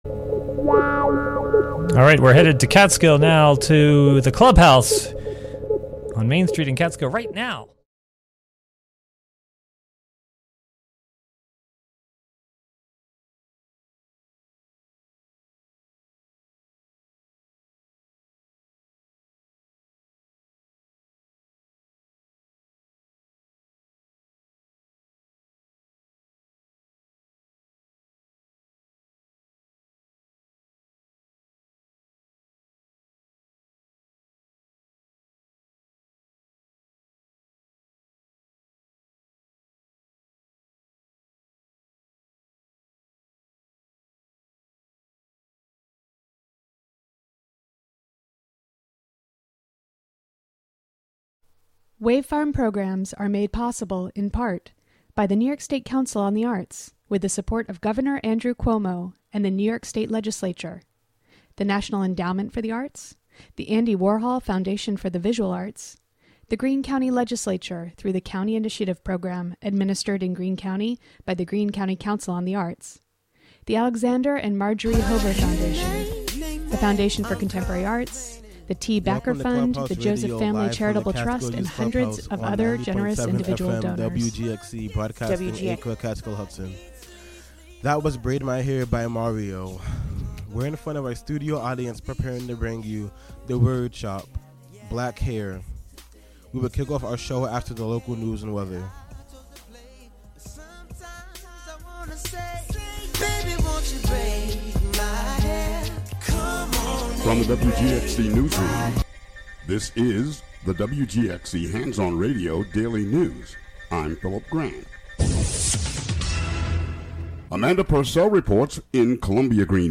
We'll have original music and poetry from the youth and Clubhouse staff as well as music, poetry, and spoken word that inspires us and talks about Natural Hair, the hair that people of color have. Last week we listened to a lot of music celebrating Black hair as well as what it means to experience cultural appropriation and microagressions as Black youth. Our container: Radiolab, an open, experimental, youth-led programming and recording space. Show includes local WGXC news at beginning, and midway through.